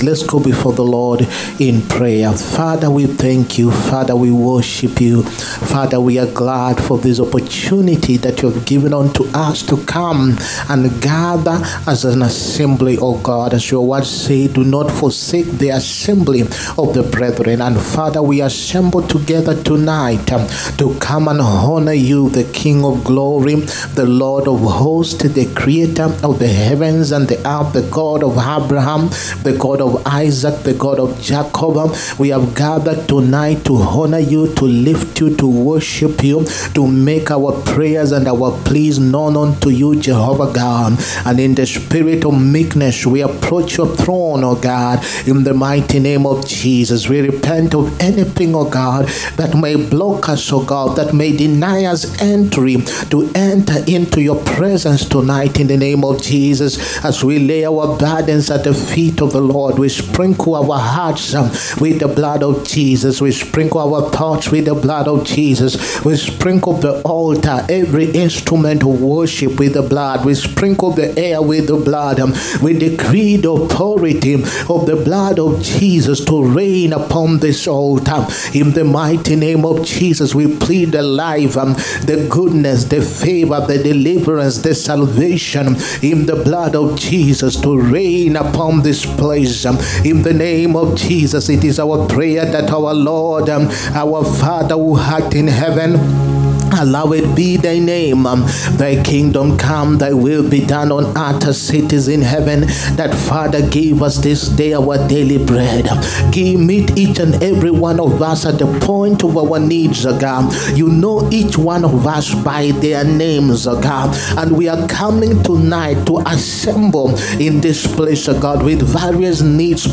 HEALING, PROPHETIC AND DELIVERANCE SERVICE. 22ND FEBRUARY 2025.